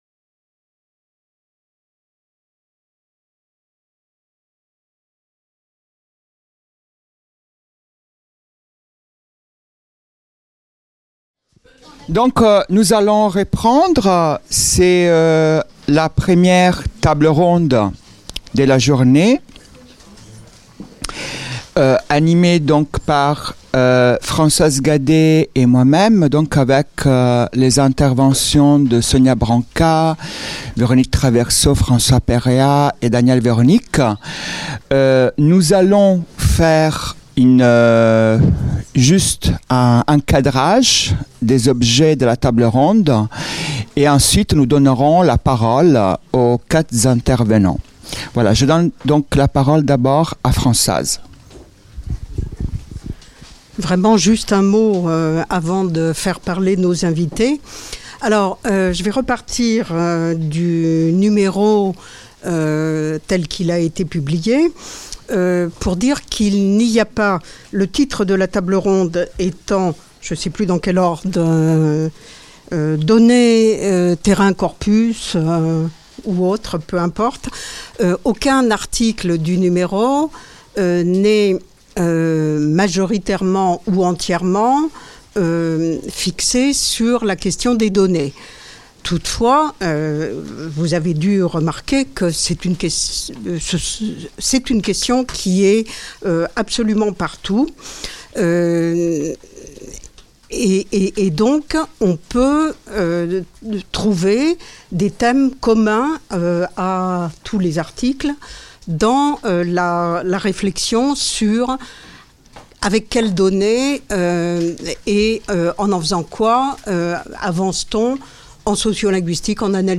A l’occasion de ses 40 ans de publication, la revue Langage et Société organise une manifestation scientifique et festive, de 9h à 20h, à la Fondation Maison des sciences de l'homme.